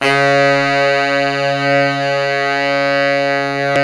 SAX_sfc#3ex2 224.wav